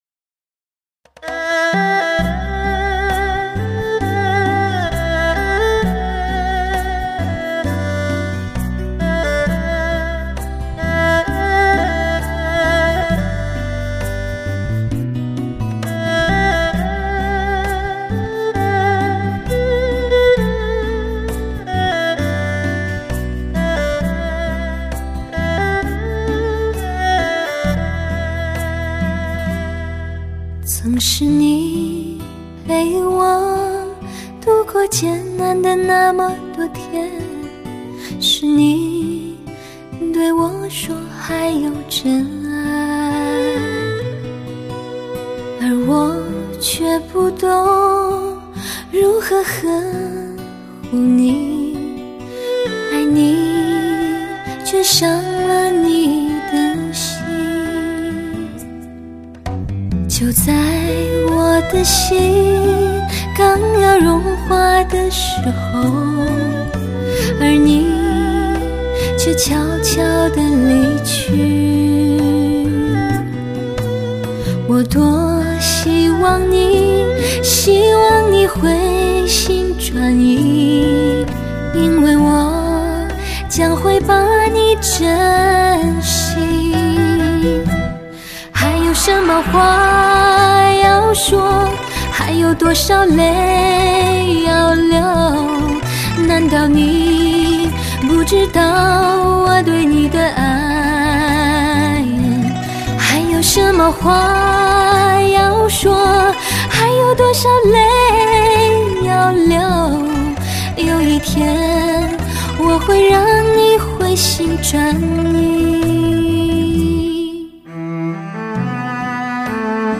天籁之音　声色入胃
发烧测试高级音响体系必备测机碟
HI-FI顶级人声测试天碟
天籁般的音乐配合动人之歌声
真正发烧极品